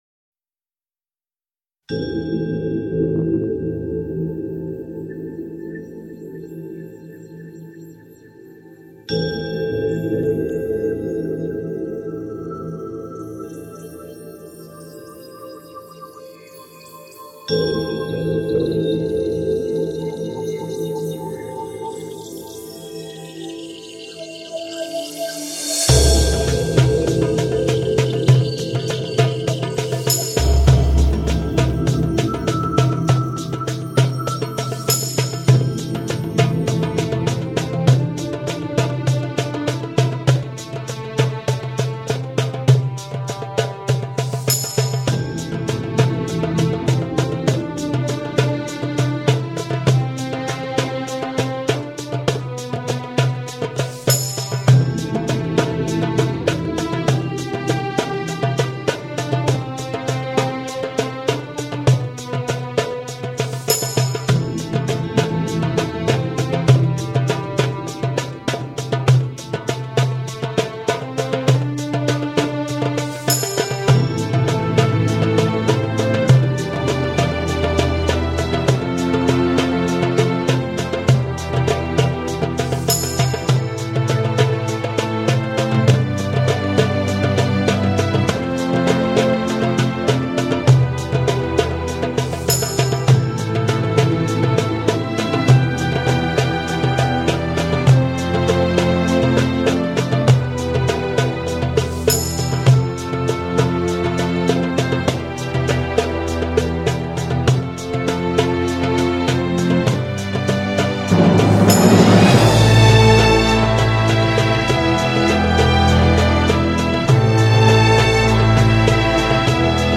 24BIT/192K DSP MASTERING
音乐包括吓人的电子声效、抒情的人声、细致优美的弦乐作品以至大型编制的古典交响曲，内容包罗万象。